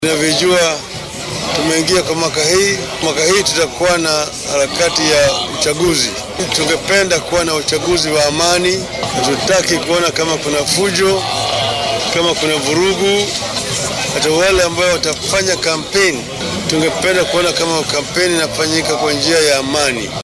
Hadalkan uu ra’iisul wasaarihii hore ee dalka ka jeediyay fagaaraha Bukhungu ee ismaamulka Kakamega halkaaso oo shalay lagu qabtay kulan siyaasadeed looga gol-lahaa in lagu mideeyo shacabka ku nool gobolka galbeedka ee wadanka.